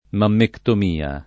mammectomia [ mammektom & a ]